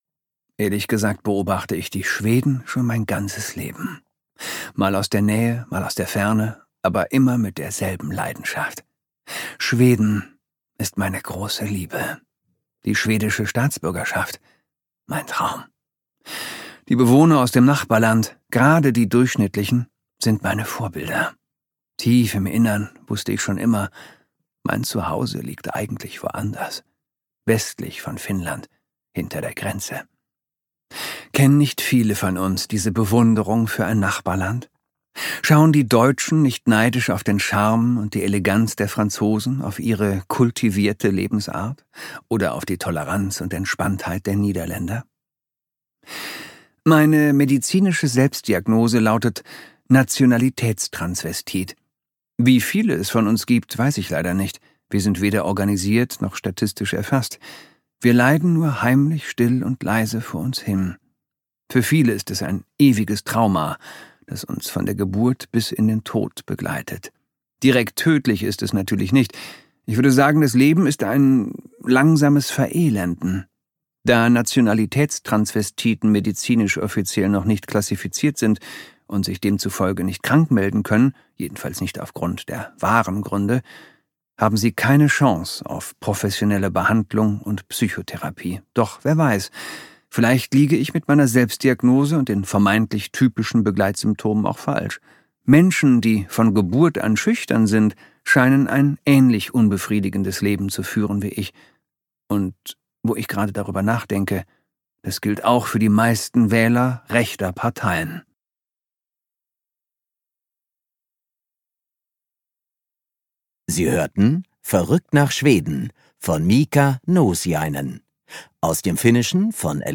Verrückt nach Schweden Miika Nousiainen (Autor) Christoph Maria Herbst (Sprecher) Audio-CD 2019 | 1.